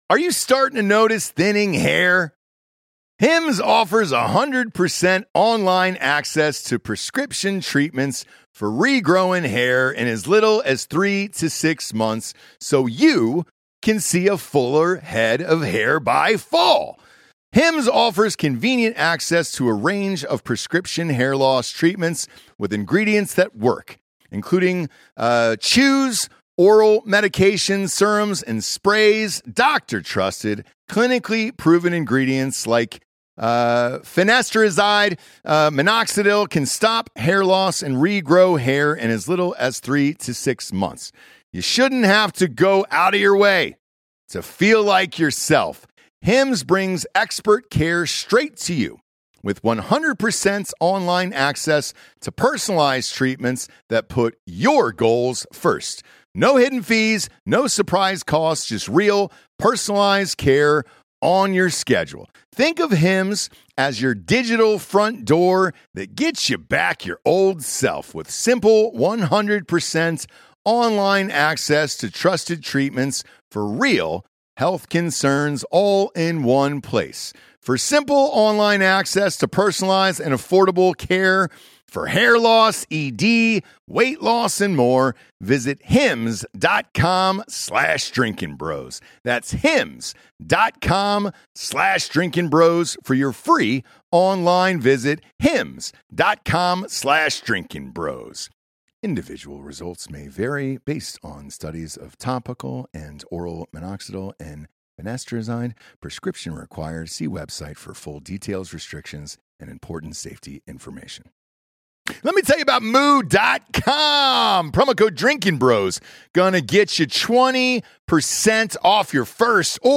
Episode 634 - DB Sports Companion Show 07/07/20 - Special Guest Aubrey Huff